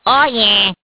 One of Waluigi's voice clips in Mario Kart DS